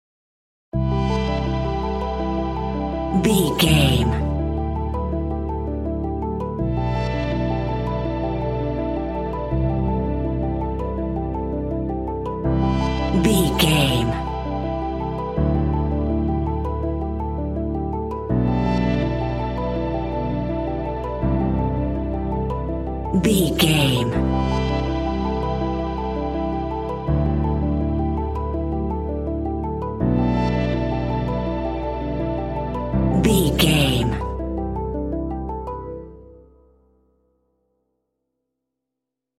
Ionian/Major
hypnotic
peaceful
ambient
synthesiser